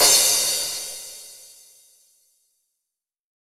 9CRASH.wav